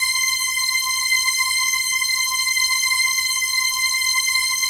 XXL 800 Pads
PHASE-PAD 07
PHASEPAD07-LR.wav